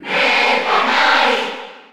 Category:Crowd cheers (SSB4) You cannot overwrite this file.
Meta_Knight_Cheer_Spanish_PAL_SSB4.ogg